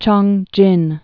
(chôngjĭn, chœng-)